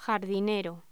Locución: Jardinero
voz